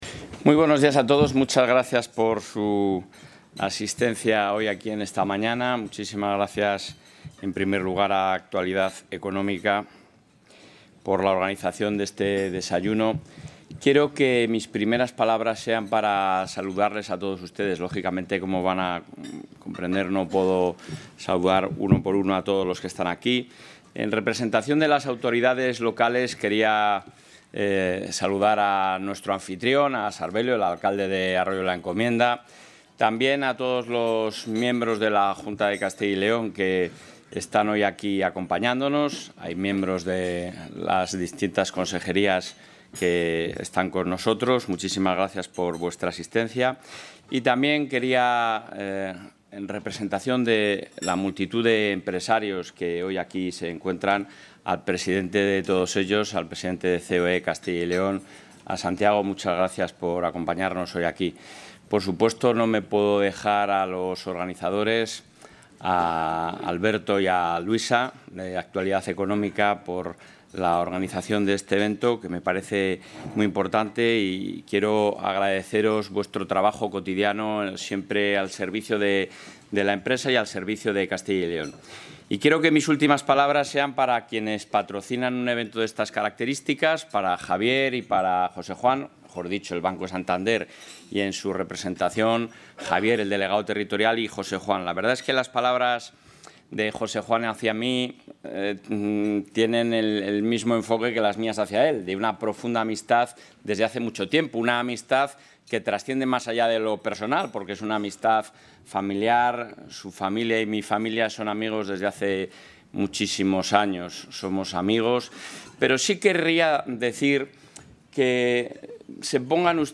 Intervención del presidente de la Junta.
El presidente de la Junta, Alfonso Fernández Mañueco, ha pronunciado hoy, en Valladolid, la conferencia ‘Castilla y León, una Comunidad atractiva para invertir y trabajar’, en el marco del Encuentro Empresarial organizado por Castilla y León Económica y el Banco Santander.